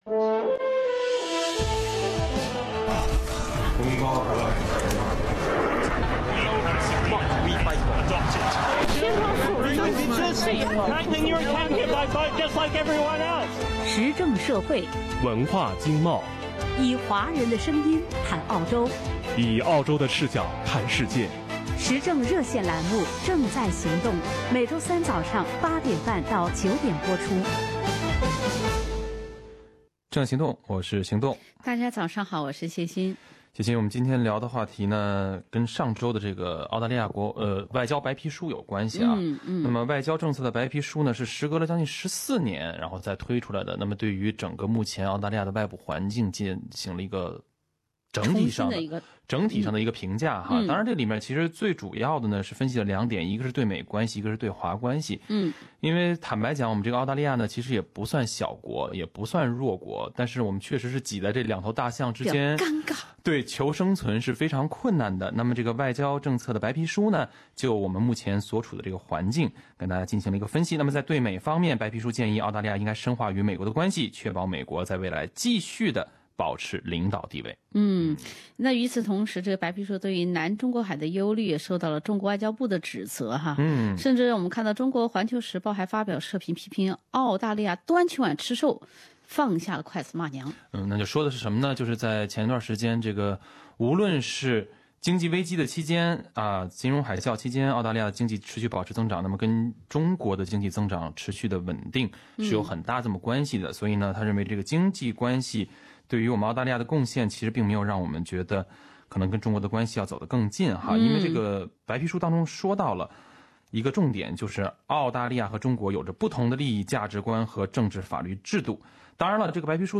听众朋友热烈讨论澳洲外交白皮书。
以下为部分热线听众的观点：